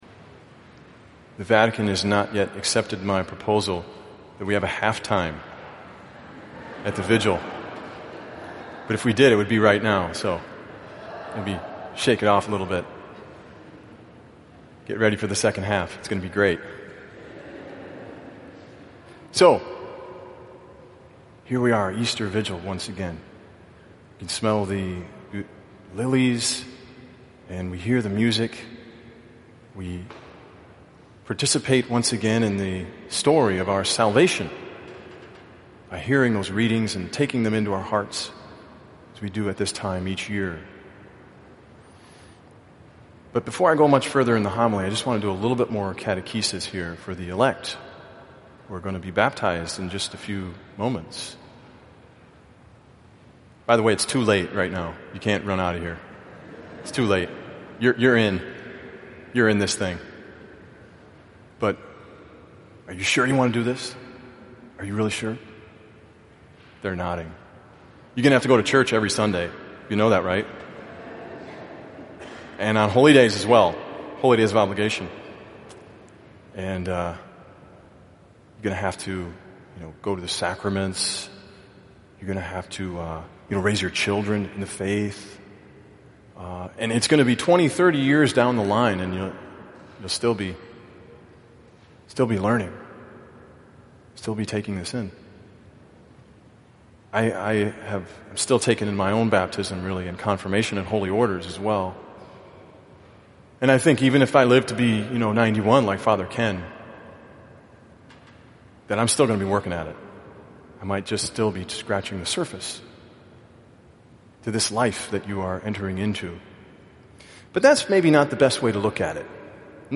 POSTS: Video Commentaries & Homilies (Audio)
Easter Vigil 2015